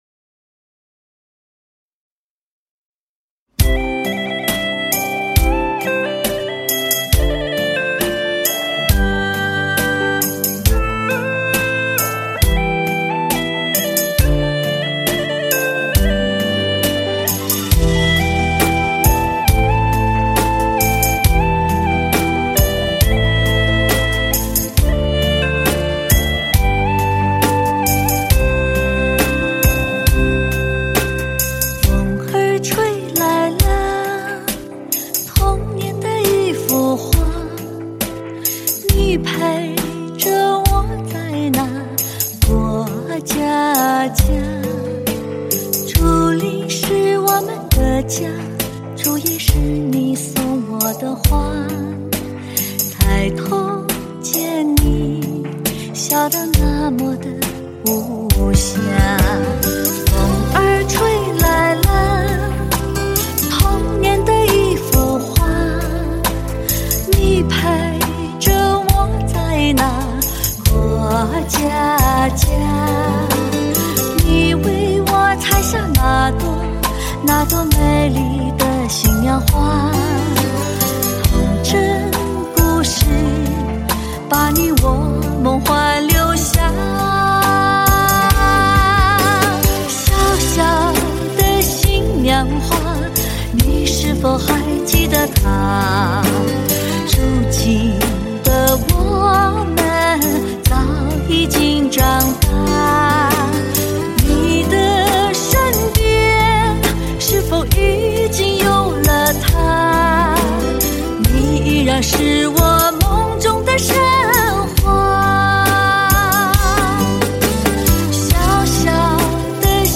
Ps：在线试听为压缩音质节选，体验无损音质请下载完整版 无歌词